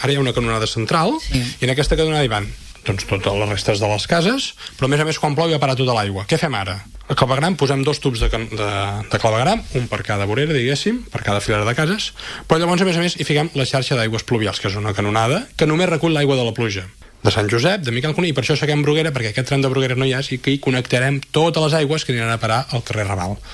L’alcalde i responsable de Territori, Marc Buch, ha explicat exactament quina és la intervenció que es farà per acabar amb els problemes de col·lapse del clavegueram i desbordaments que pateix el veïnat en dies de pluja intensa.